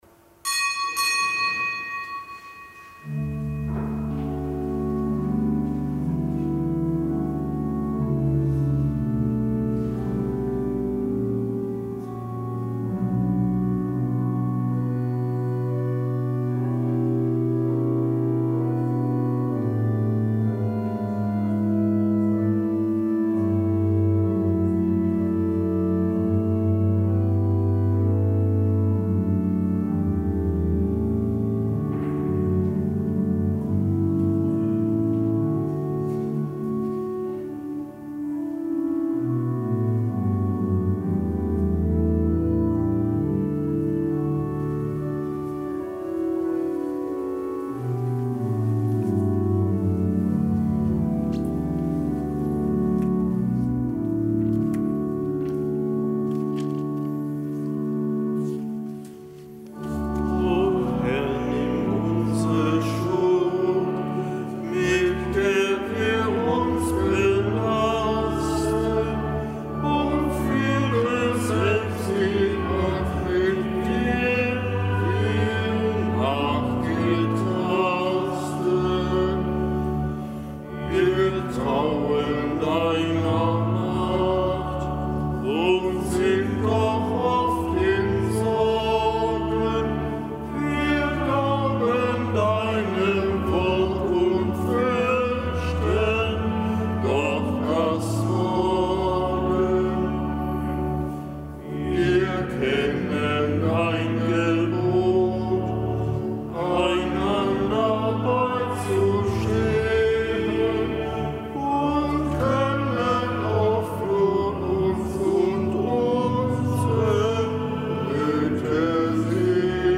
Kapitelsmesse am Samstag nach Aschermittwoch
Kapitelsmesse aus dem Kölner Dom am Samstag nach Aschermittwoch. Nichtgebotener Gedenktag des Heiligen Petrus Damiani, Bischof und Kirchenlehrer.